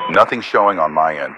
Radio-pilotWingmanReportContactsNoJoy4.ogg